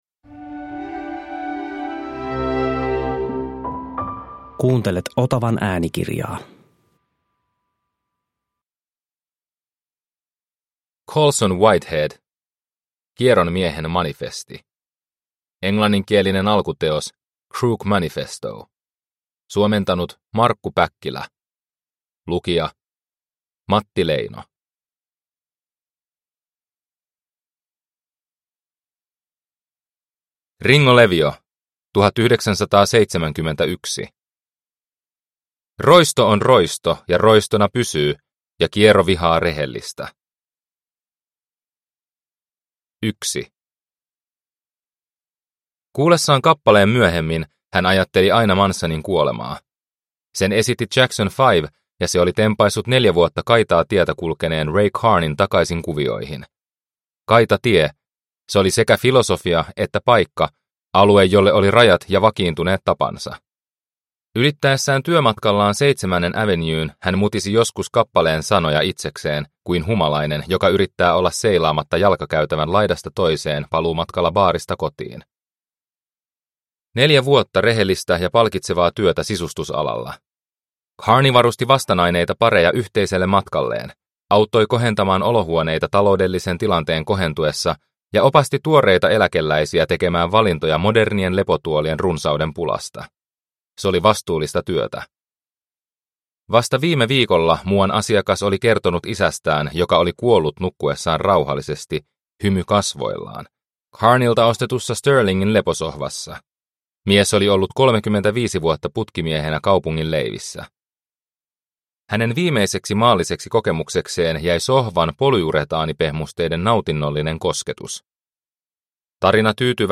Kieron miehen manifesti – Ljudbok – Laddas ner